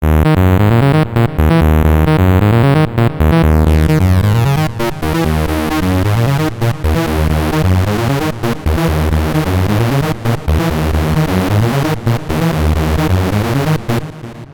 Demonstration of using detune in legacy mode on six oscillators (stereo - compare with finetune example above):